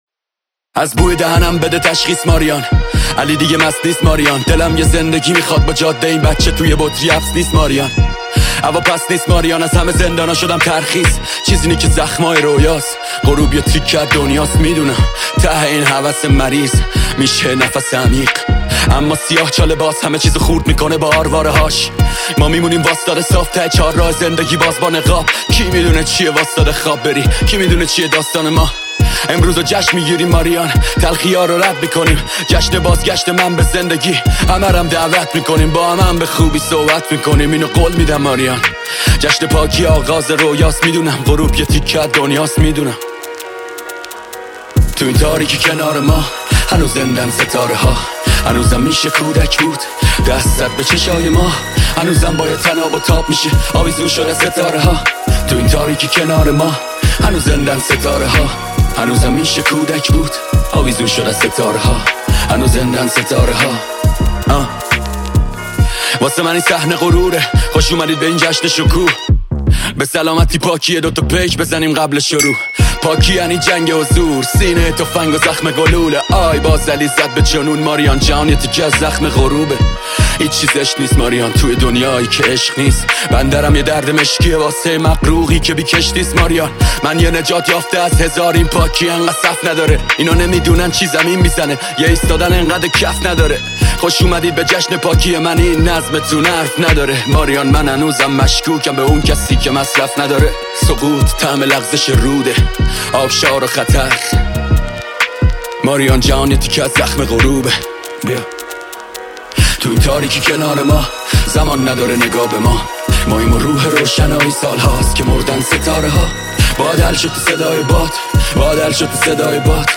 اهنگ رپ